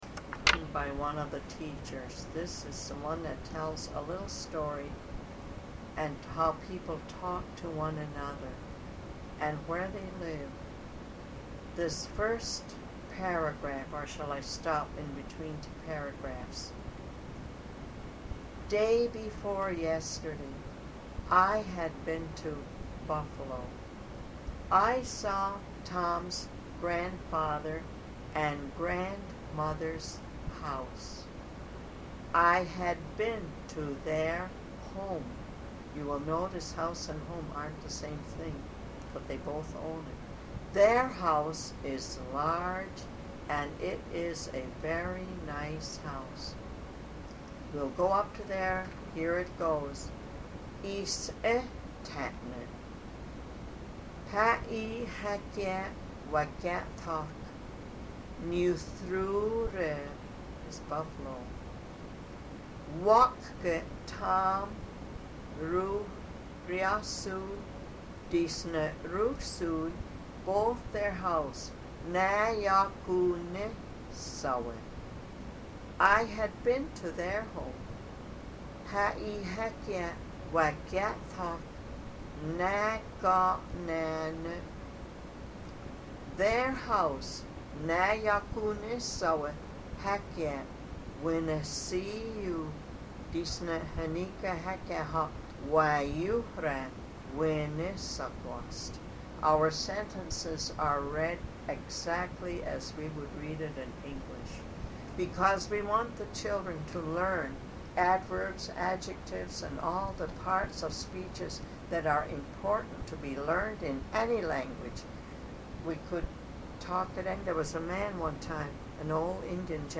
Story given in English and Tuscarora with explanation
01-02 Story given in English and Tuscarora with explanation.mp3